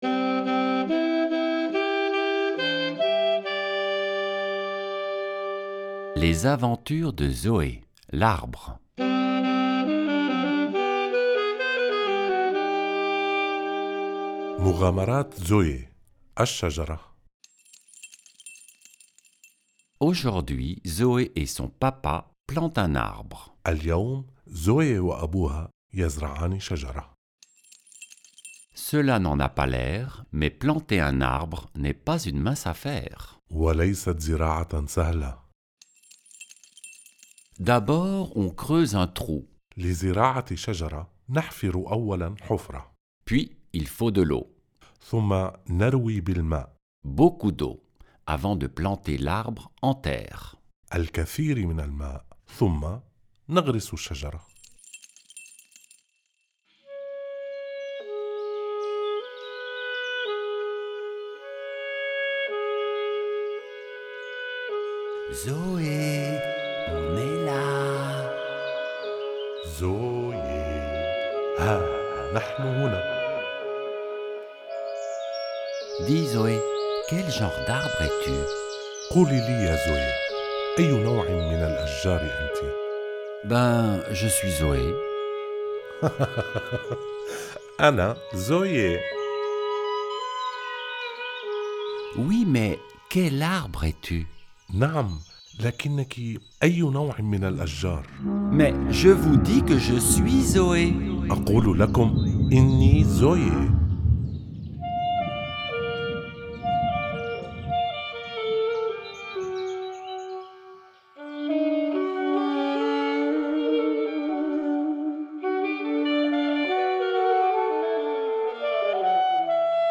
création musicale, enregistrement et mixage de la musique
lecture en français
lecture en arabe